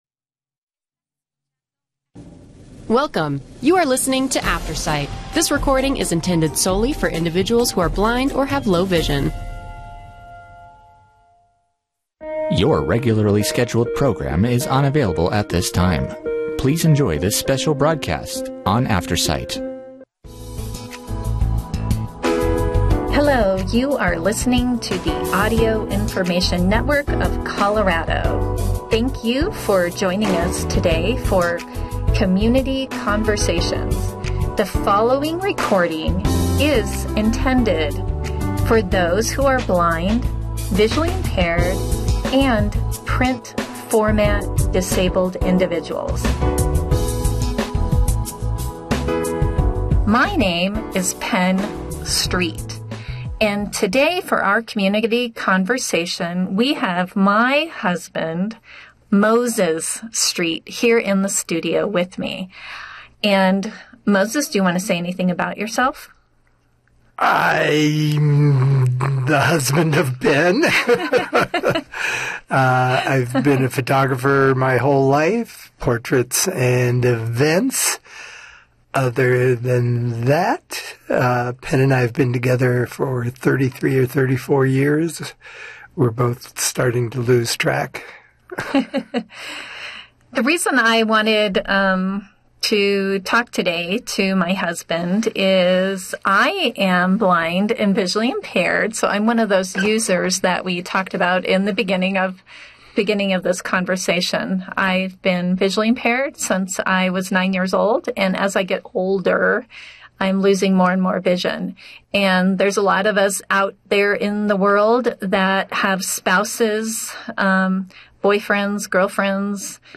Play Rate Listened List Bookmark Get this podcast via API From The Podcast Safeway weekly ad in audio format.